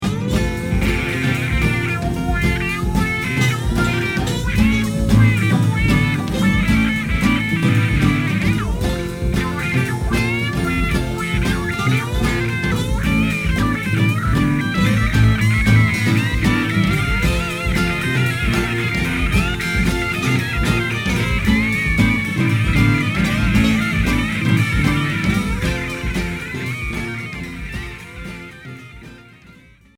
Progressif psychédélique Unique 45t retour à l'accueil